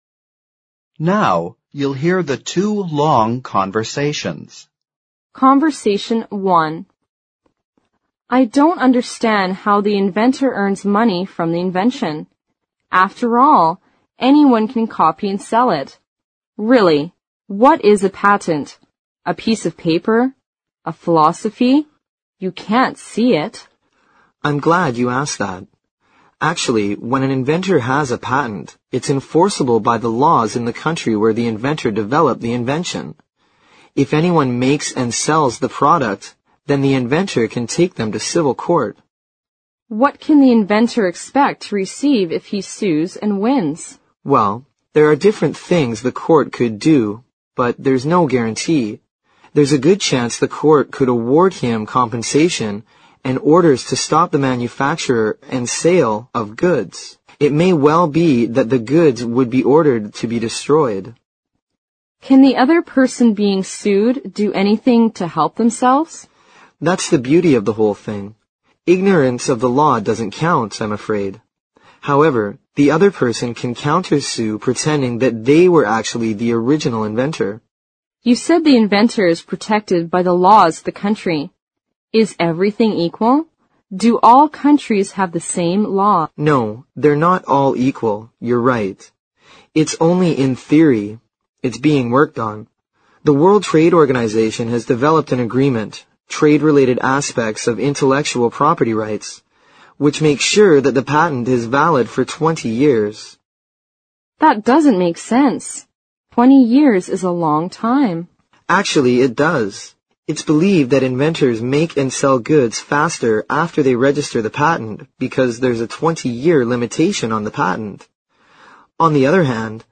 Conversation One